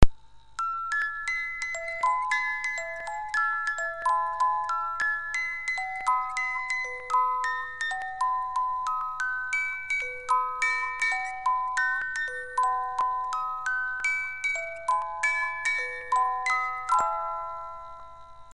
Boite à musique suisse bois
Boite à musique Suisse